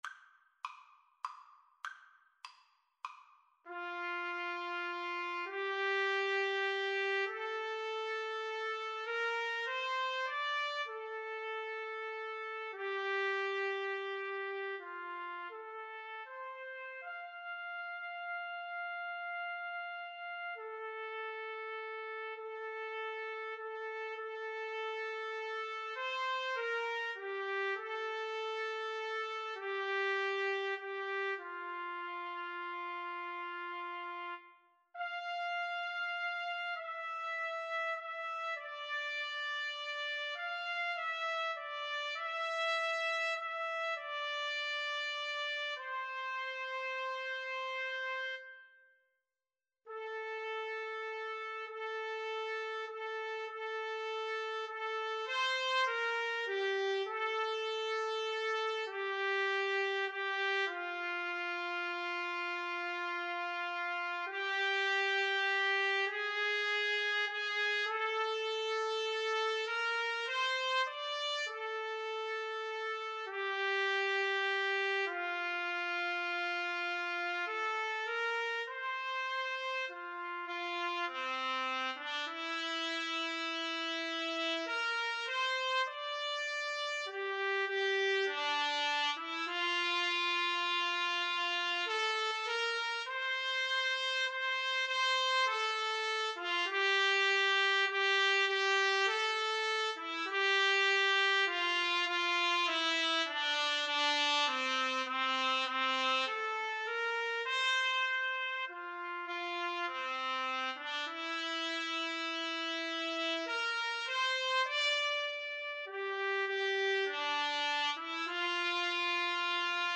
Trumpet 1Trumpet 2
3/4 (View more 3/4 Music)
Moderato
Traditional (View more Traditional Trumpet Duet Music)
world (View more world Trumpet Duet Music)